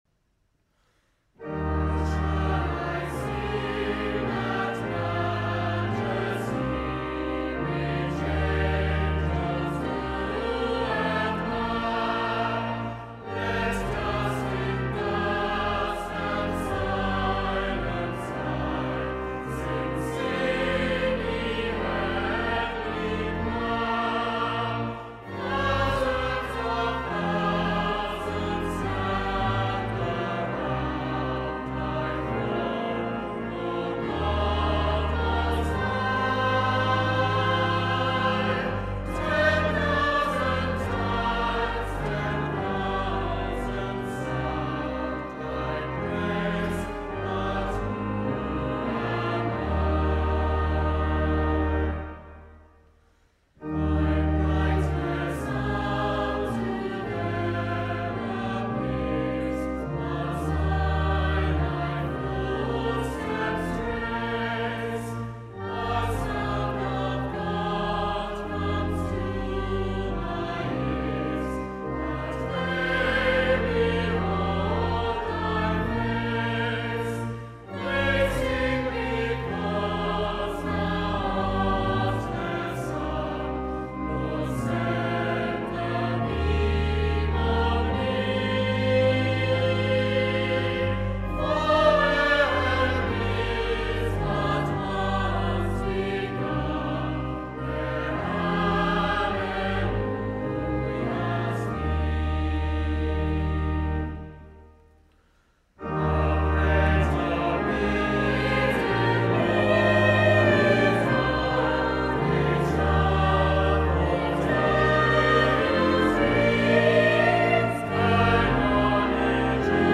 School Choir Singing ‘How Shall I Sing That Majesty’ to the tune ‘Coe Fen’ by Ken Naylor: